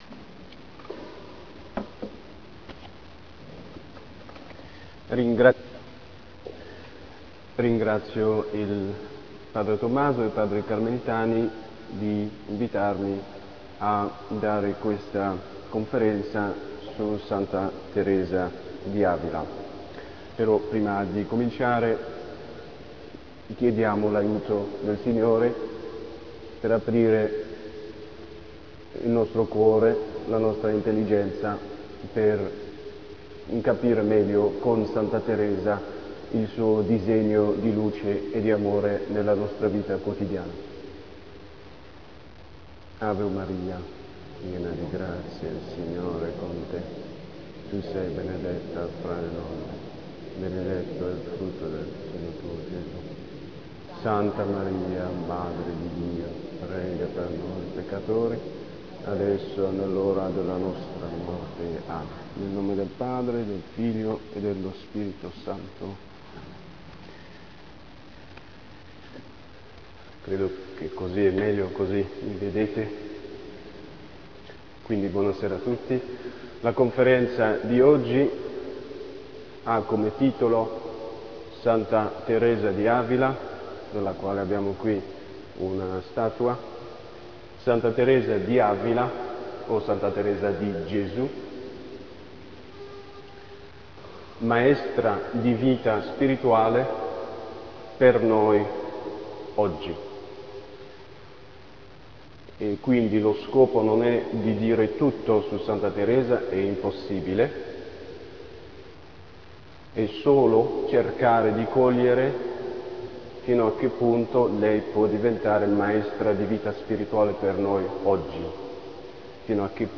Legnano - SABATO 14 GENNAIO 2006